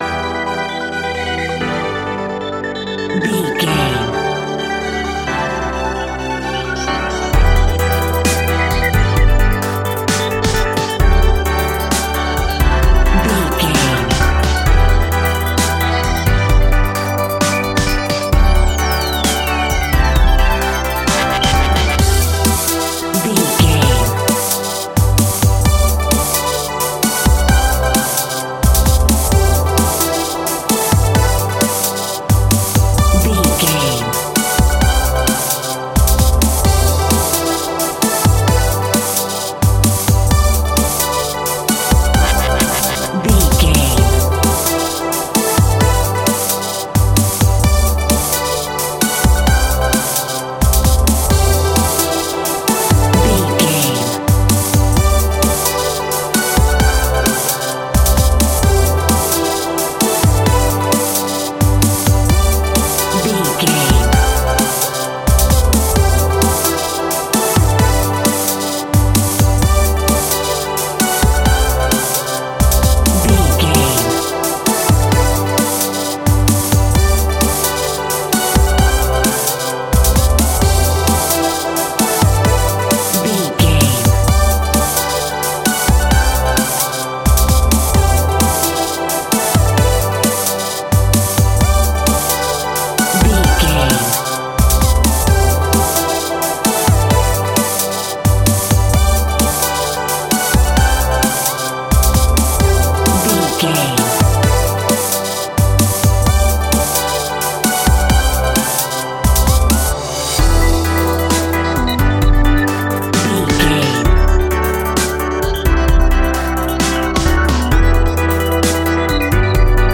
Aeolian/Minor
frantic
driving
energetic
hypnotic
industrial
powerful
drum machine
synth leads
electronic music
techno music
synth bass
synth pad
robotic